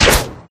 Bow1.ogg